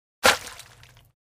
Звук раздавливания предмета под ударом ноги